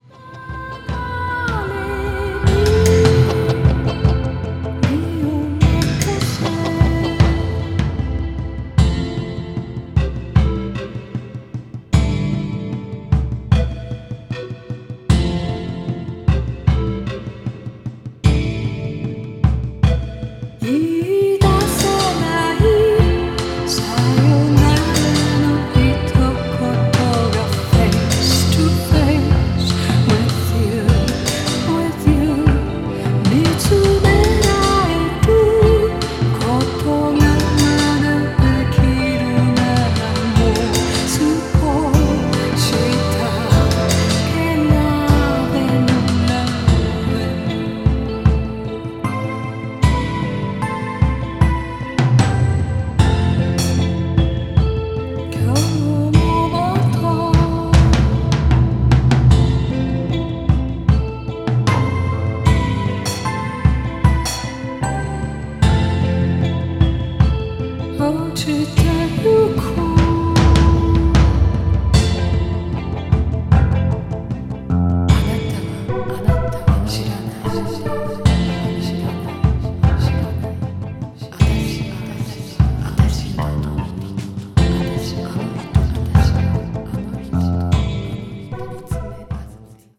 A delicate and melancholic finish